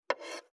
550魚切る,肉切りナイフ,まな板の上,
効果音厨房/台所/レストラン/kitchen食器食材